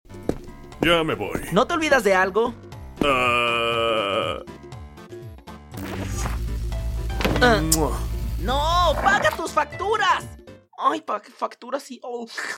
¡PAGA TUS FACTURAS!🍕 FORSAKEN FANDUB sound effects free download